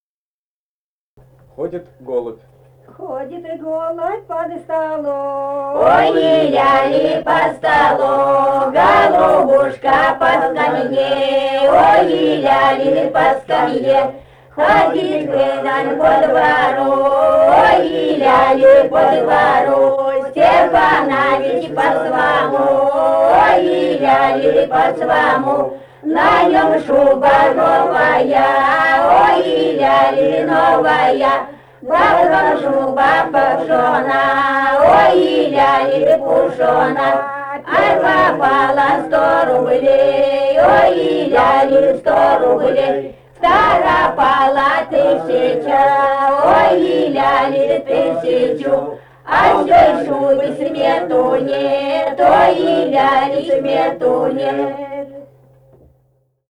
Этномузыкологические исследования и полевые материалы
«Ходит голубь по столу» (свадебная).
Самарская область, с. Кураповка Богатовского района, 1972 г. И1318-19